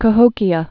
(kə-hōkē-ə)